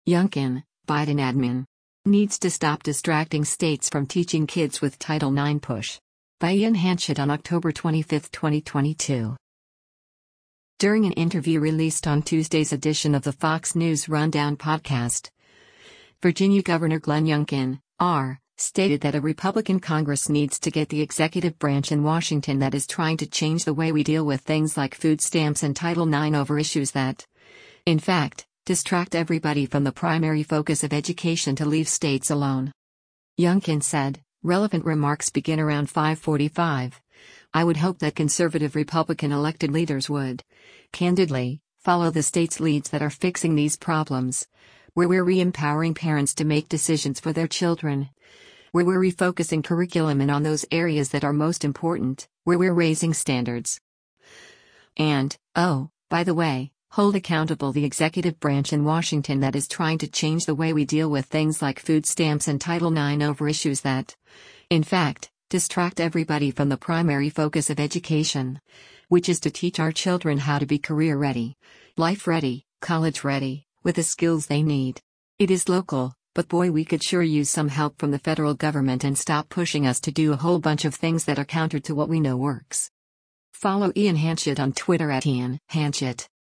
During an interview released on Tuesday’s edition of the “Fox News Rundown” podcast, Virginia Gov. Glenn Youngkin (R) stated that a Republican Congress needs to get “the executive branch in Washington that is trying to change the way we deal with things like food stamps and Title IX over issues that, in fact, distract everybody from the primary focus of education” to leave states alone.